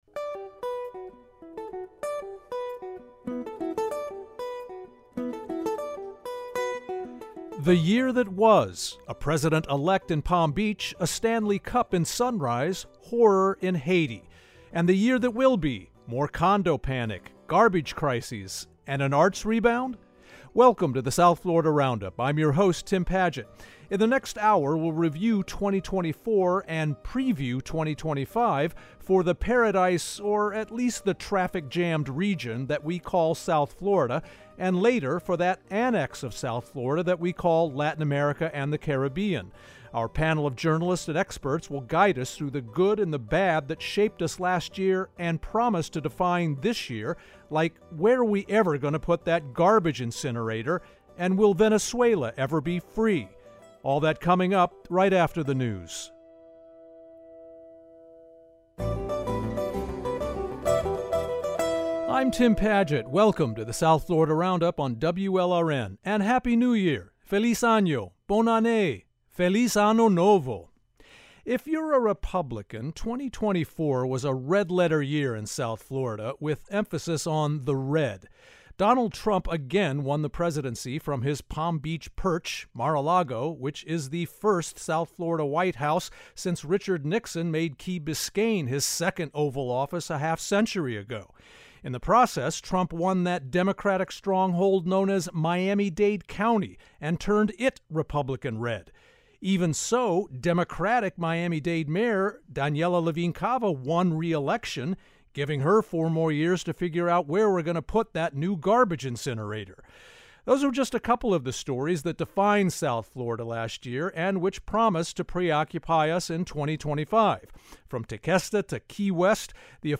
1 The year that 2024 was - and the year that 2025 will be - in South Florida 50:55 Play Pause 1d ago 50:55 Play Pause Riproduci in seguito Riproduci in seguito Liste Like Like aggiunto 50:55 On this week's episode of The South Florida Roundup, we reviewed 2024, and previewed 2025, for the paradise — or at least the traffic-jammed region — that we call South Florida (01:13), as well as for that annex of South Florida that we call Latin America and the Caribbean (34:47). Our panel of journalists and experts guided us through the good and…